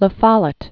(lə fŏlət), Robert Marion Known as "Fighting Bob." 1855-1925.